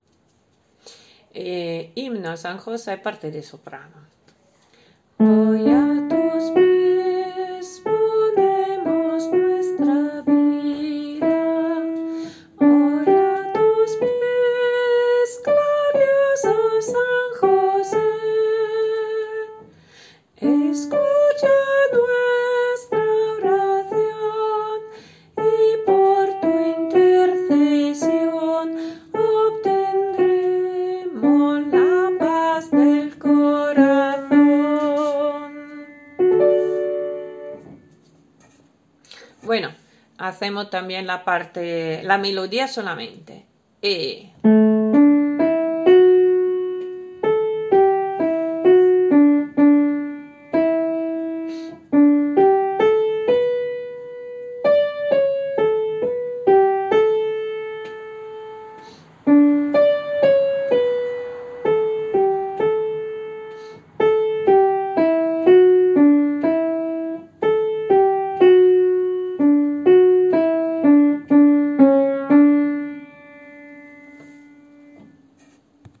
SOPRANO
h-sanjose-soprano.mp3